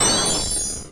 1fbff5f83b23d39d38b1dfcb4cac8d9b 00c382e117 [MIRROR] Converts almost every single sound to mono 44.1khz, recuts some sounds.
barragespellhit.ogg